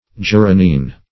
geranine - definition of geranine - synonyms, pronunciation, spelling from Free Dictionary
Search Result for " geranine" : The Collaborative International Dictionary of English v.0.48: Geraniine \Ge*ra"ni*ine\, Geranine \Ger"a*nine\, n. [See Geranium .]